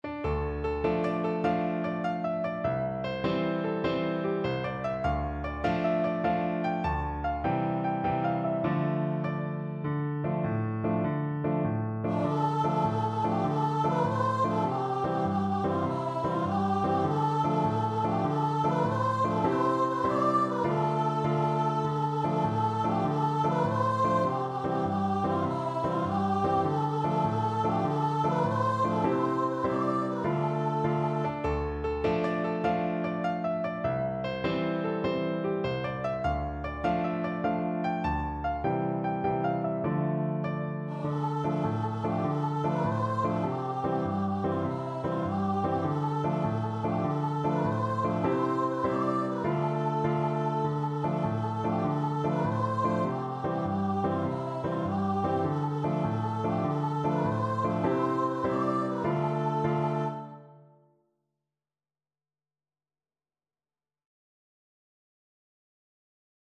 Allegro .=c.100 (View more music marked Allegro)
6/8 (View more 6/8 Music)
Traditional (View more Traditional Voice Music)